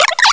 -Reintroduced the Gen. 4 and 5 cries.